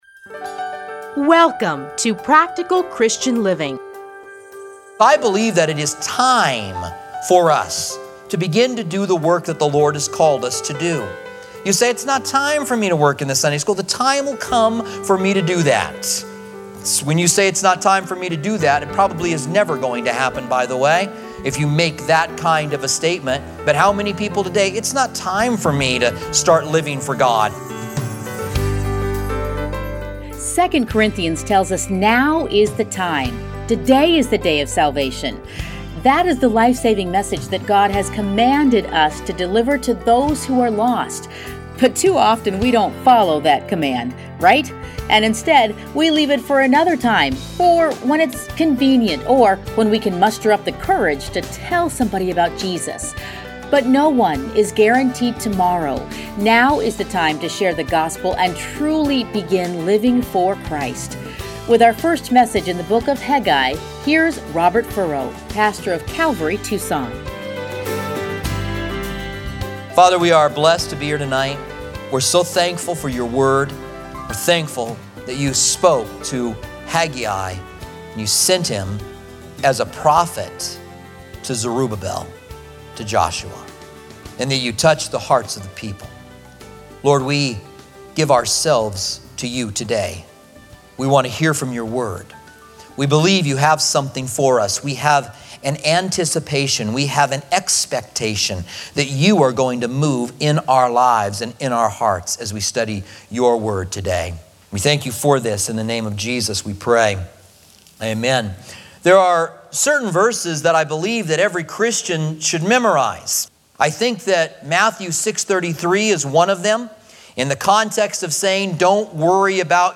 Playlists Commentary on Haggai Download Audio